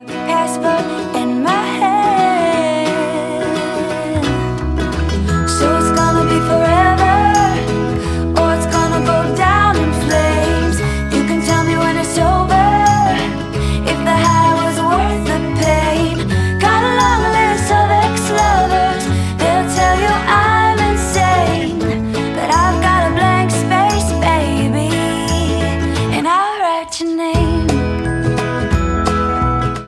• Pop, Jaz